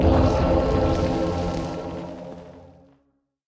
Minecraft Version Minecraft Version snapshot Latest Release | Latest Snapshot snapshot / assets / minecraft / sounds / mob / evocation_illager / prepare_summon.ogg Compare With Compare With Latest Release | Latest Snapshot
prepare_summon.ogg